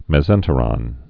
(mĕz-ĕntə-rŏn, mĕs-)